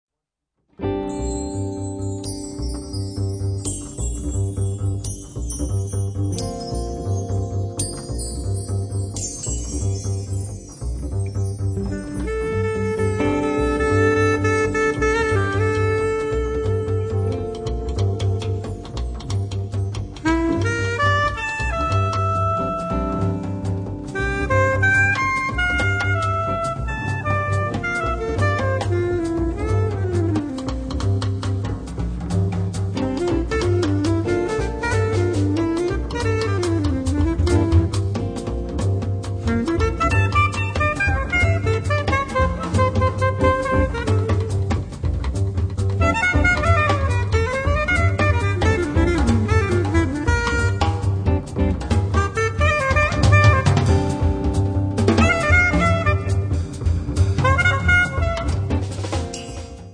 sax tenore e soprano, live electronics
chitarra
contrabbasso
batteria e percussioni